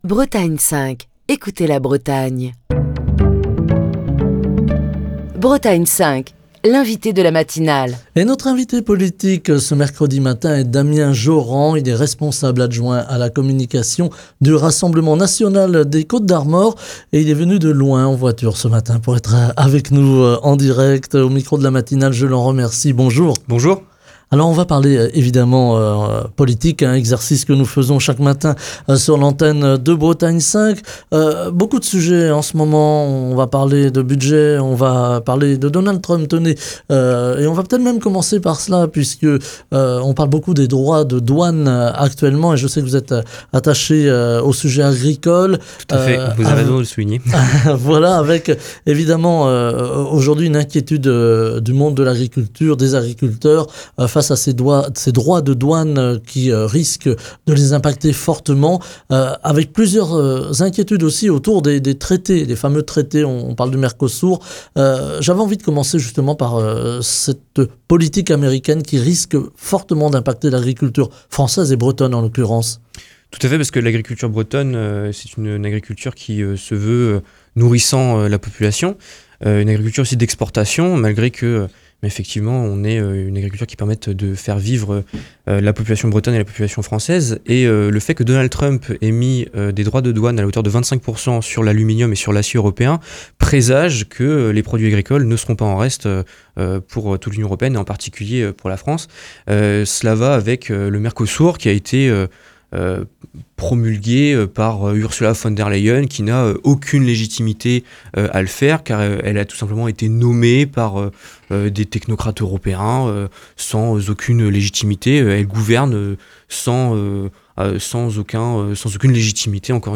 Émission du 12 février 2025.